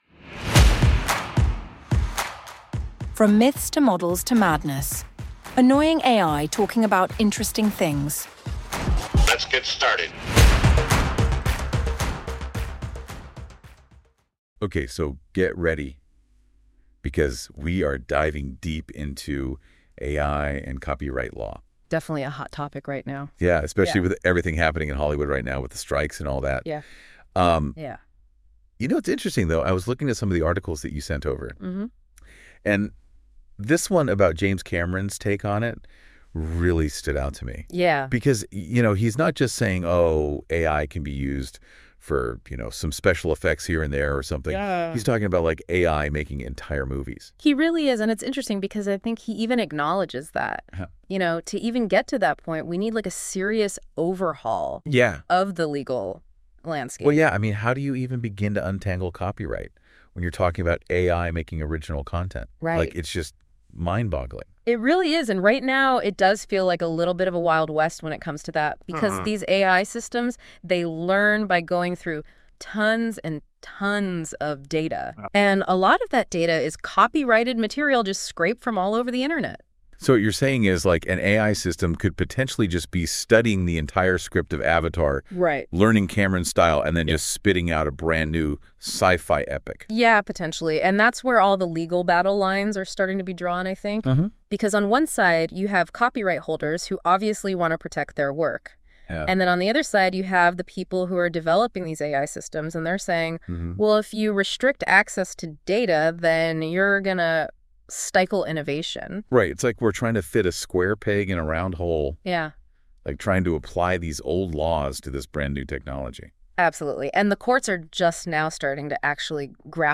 From Myths To Models To Madness: Annoying AI Talking about Interesting Things